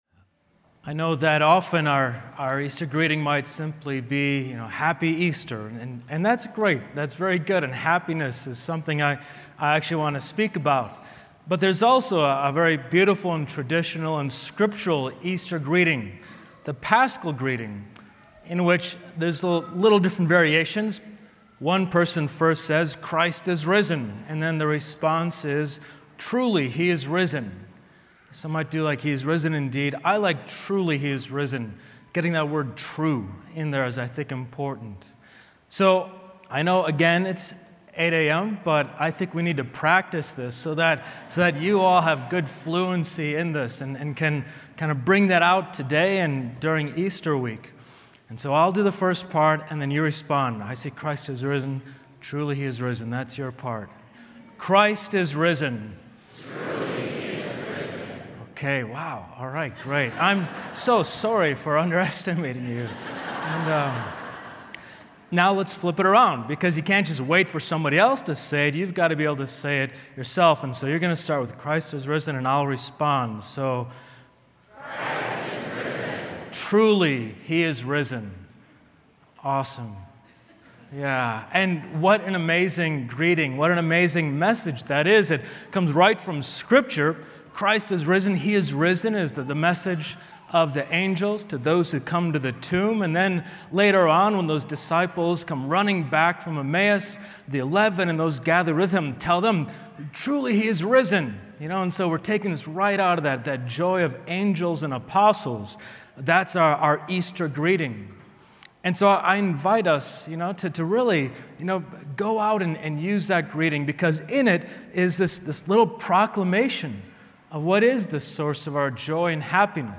Homilies